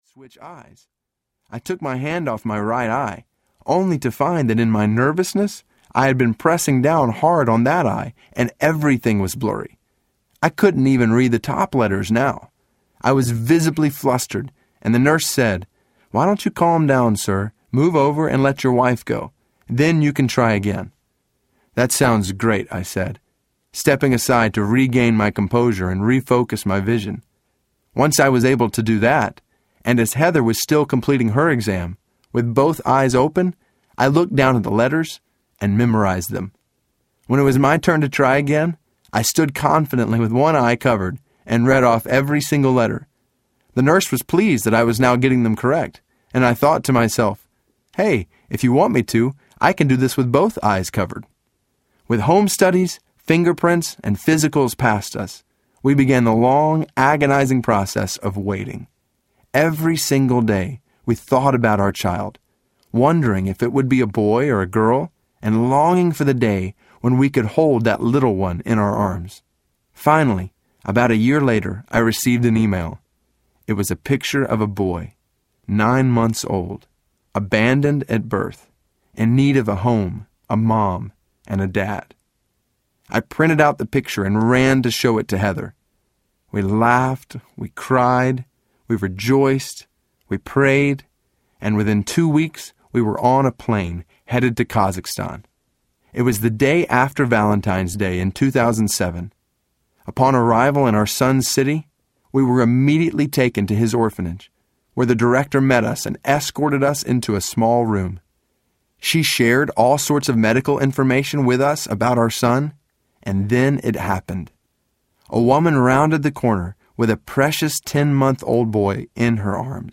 Follow Me Audiobook
7.4 Hrs. – Unabridged